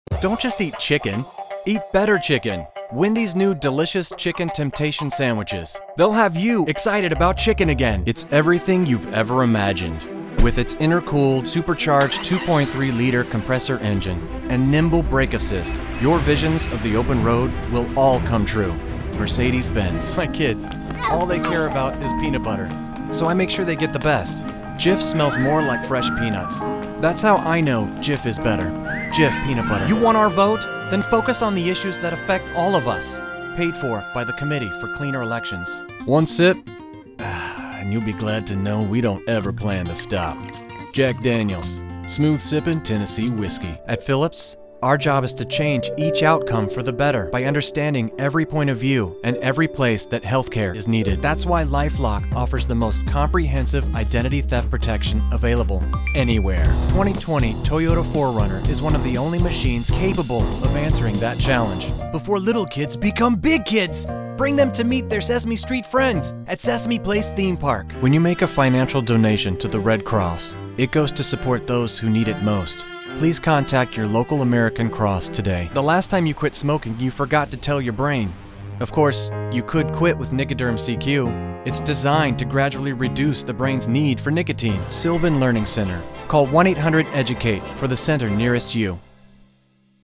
Friendly, Warm, Conversational.
Commercial